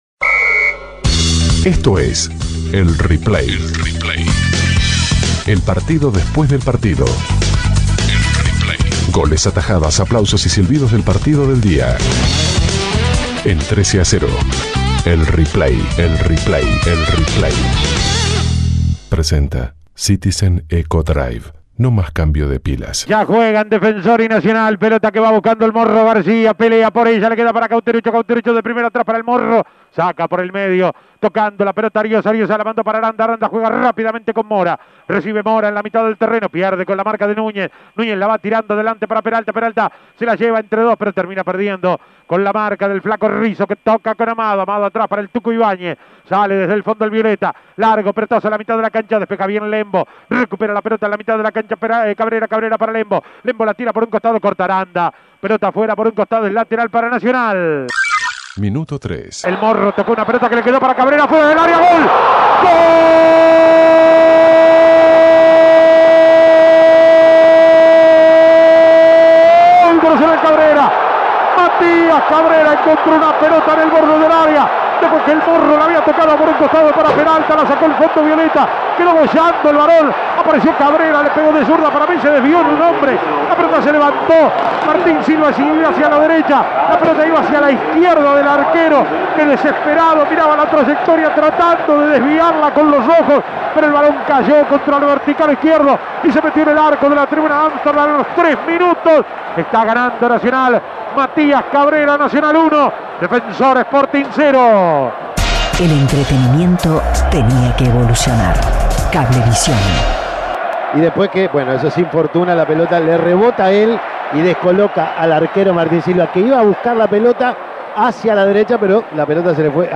Goles y comentarios Escuche el replay de Defensor - Nacional Imprimir A- A A+ Nacional derrotó a Defensor Sporting en el Estadio Centenario.